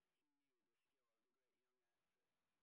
sp02_street_snr20.wav